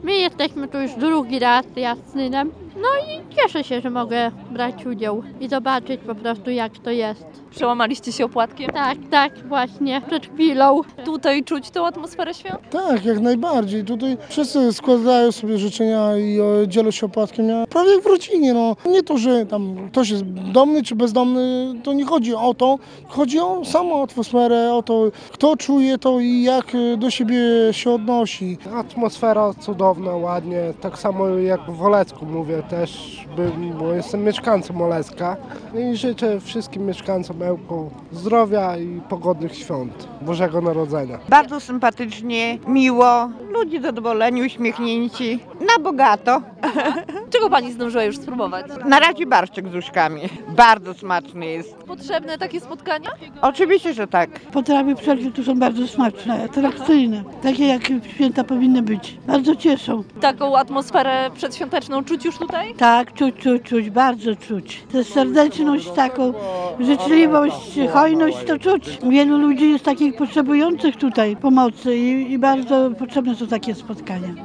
-To potrzebna inicjatywa, bo nie każdy ma możliwość spędzenia rodzinnych świąt przy suto zastawionym stole- zwracają uwagę osoby, które wzięły udział w „Wigilii pod gwiazdami” w Ełku.